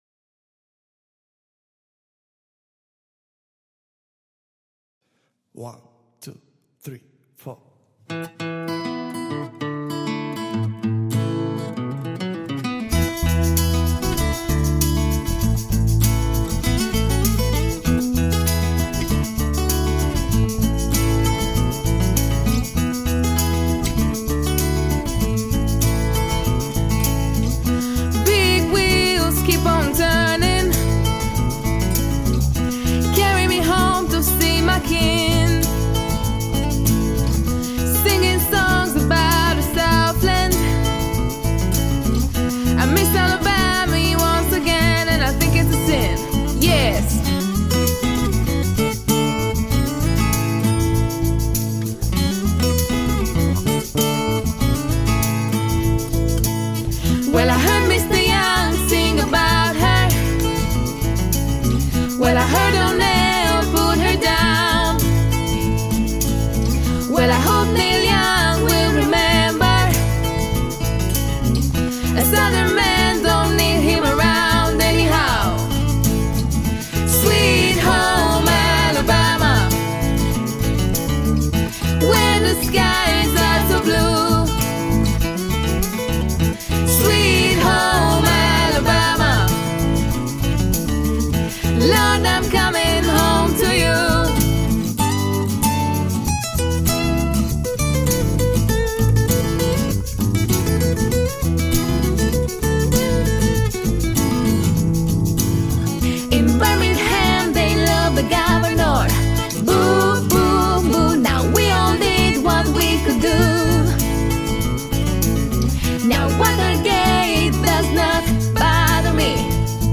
Singers:
Guitar Players: